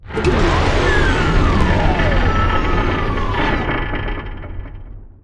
rocket.wav